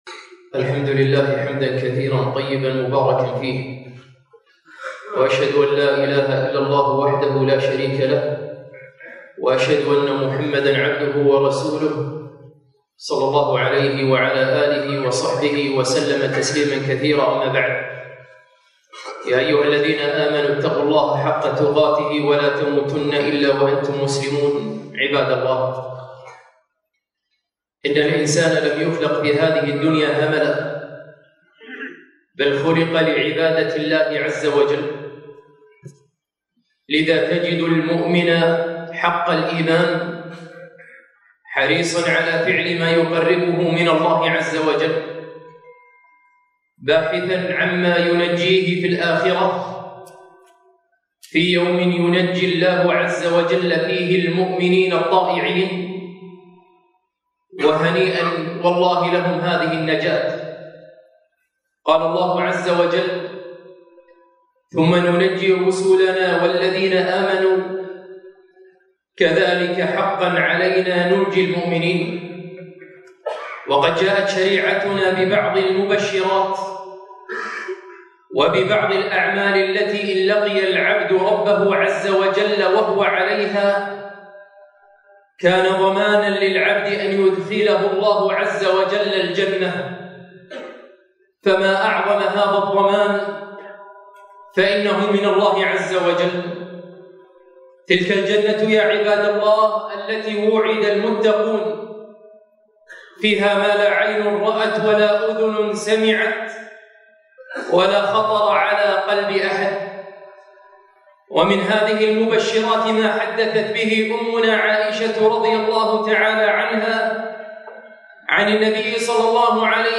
خطبة - احرصوا على هذه الخصلات الست